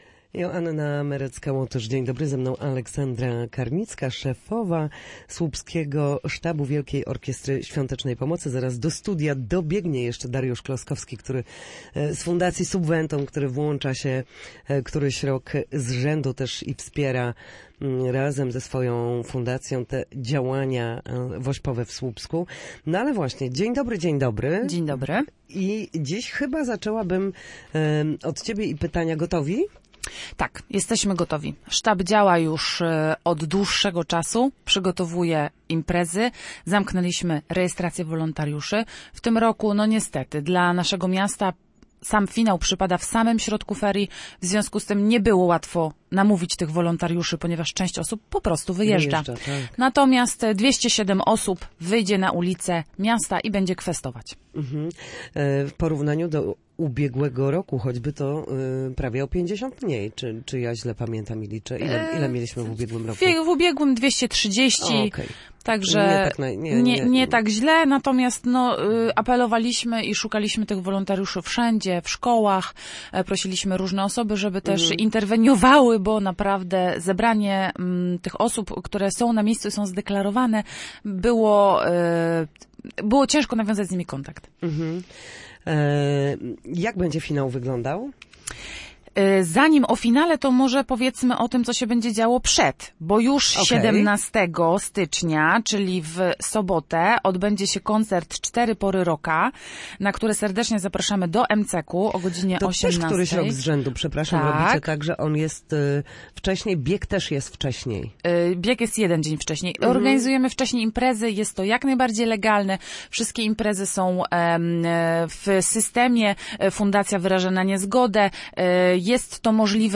Na naszej antenie mówili o zbliżającym się finale WOŚP, działaniach w Słupsku, wolontariuszach i biegu towarzyszącym wydarzeniu.